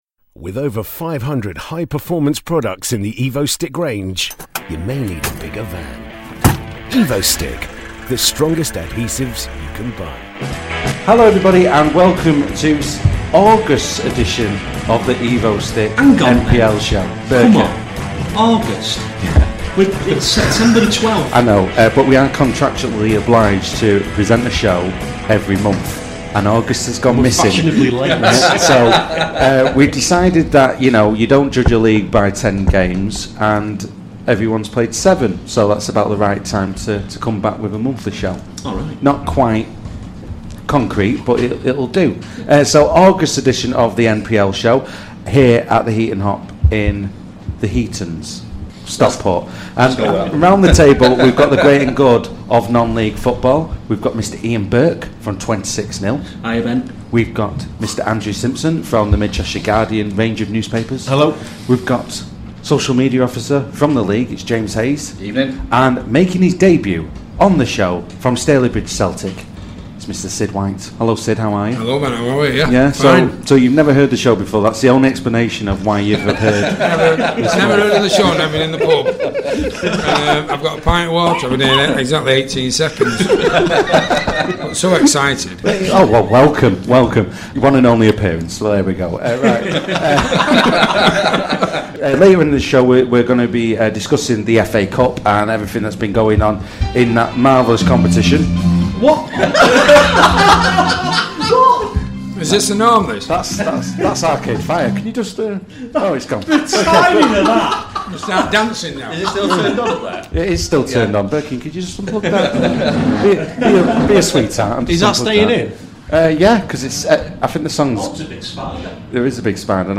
This programme was recorded at the Heaton Hops on Wednesday 12th September 2018.